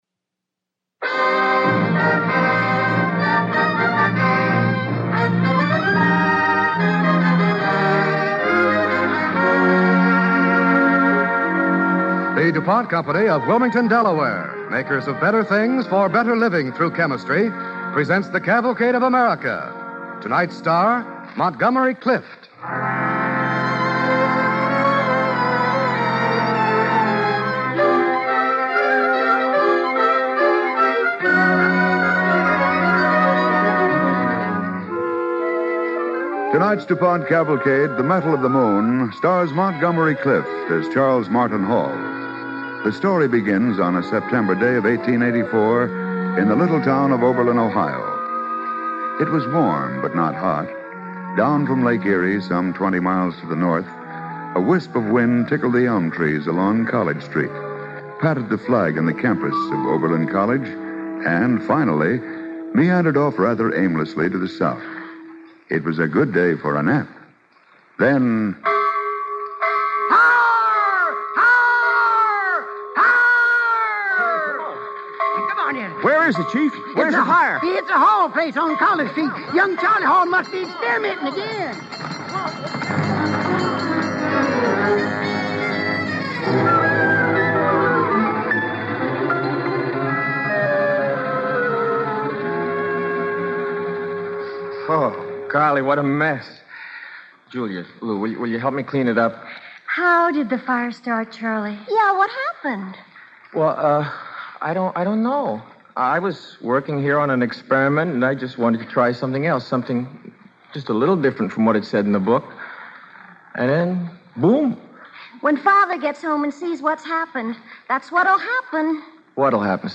Cavalcade of America Radio Program
The Metal of the Moon, starring Montgomery Clift